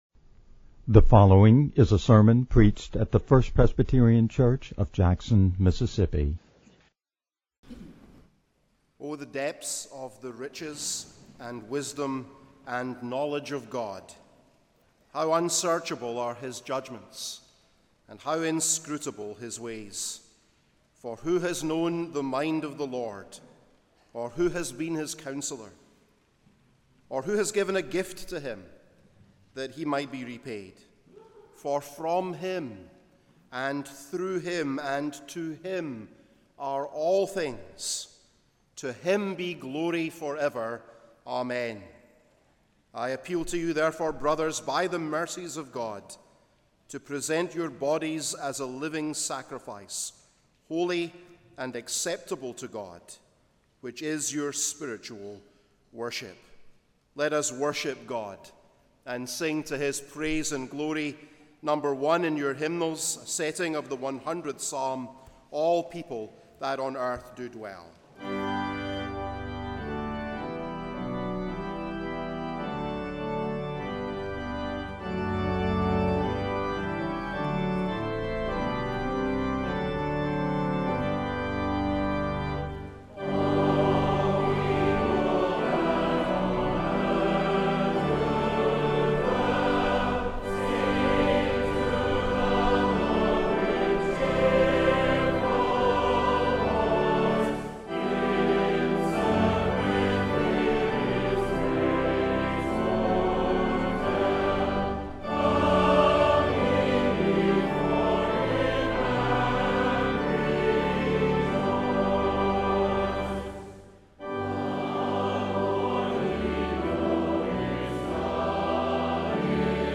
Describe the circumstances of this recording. FPC2023-REFormation-day-service.mp3